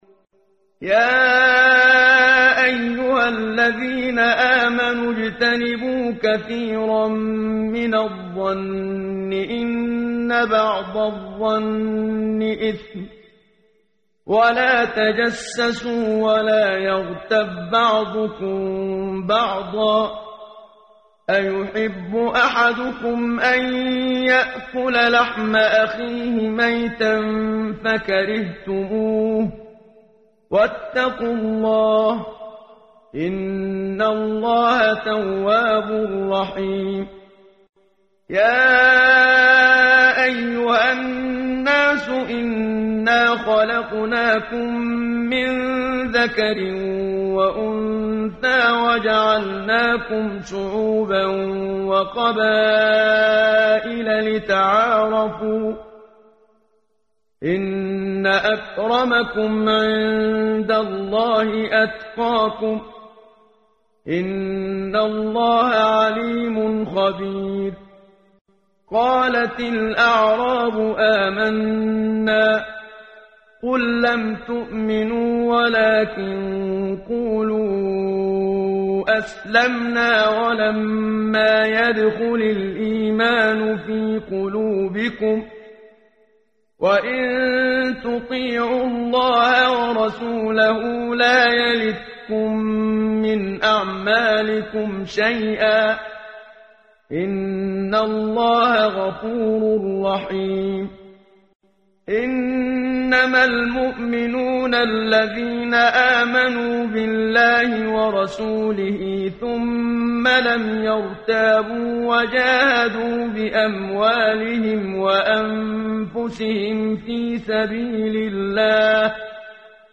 قرائت قرآن کریم ، صفحه 517 ، سوره مبارکه « الحجرات» آیه 12 تا 18 با صدای استاد صدیق منشاوی.